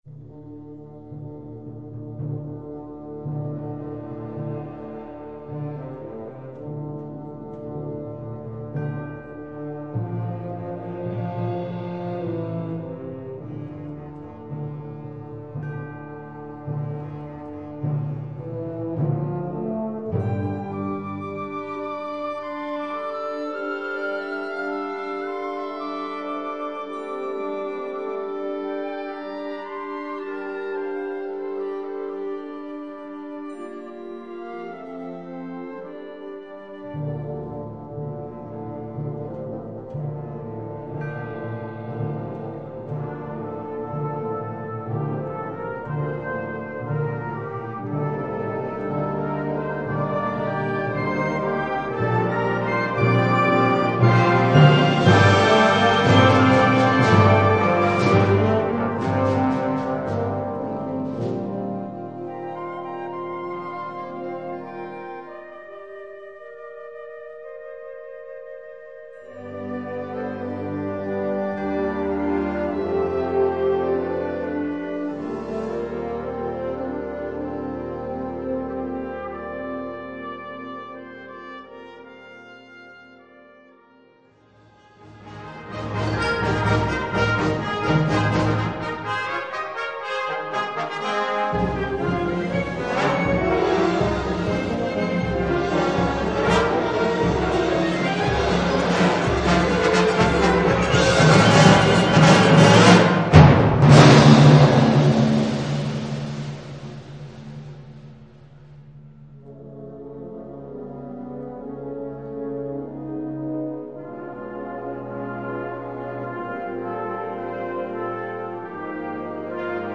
9:38 Minuten Besetzung: Blasorchester Zu hören auf